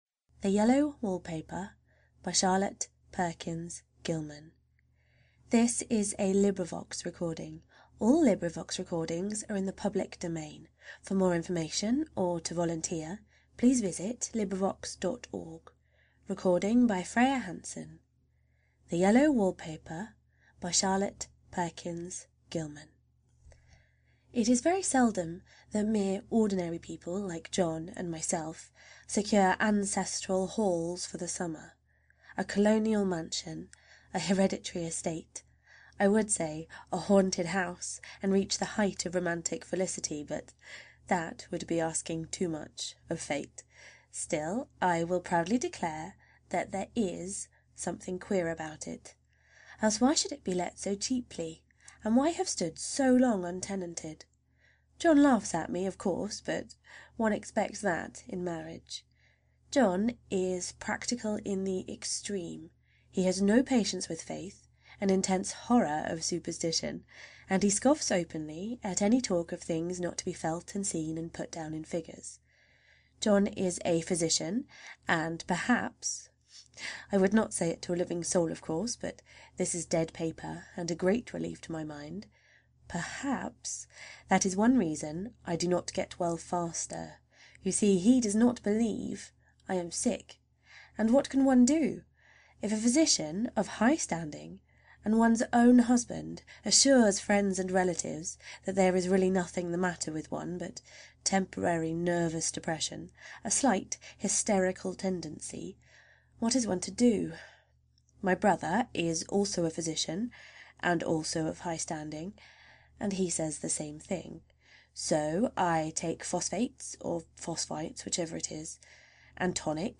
the-yellow-wallpaper-by-charlotte-perkins-gilman-_-full-length-audiobook.mp3